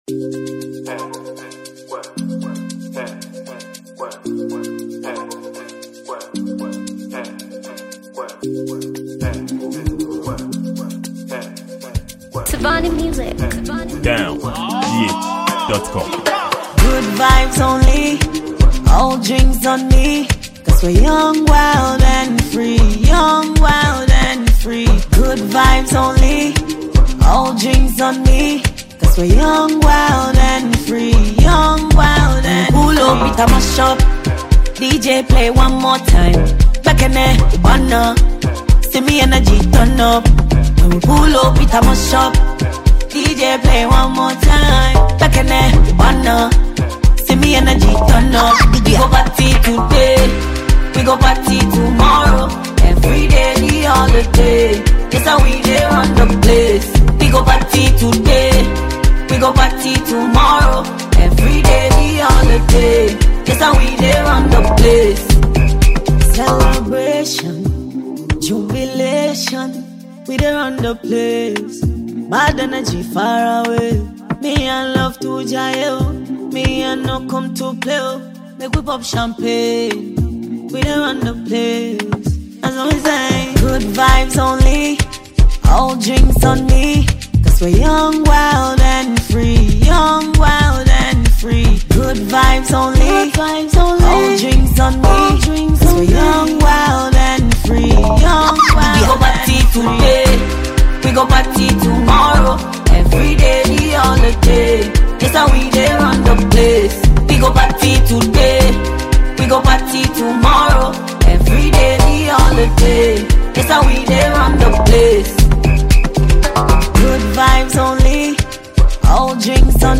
Ghanaian female afrobeat dancehall diva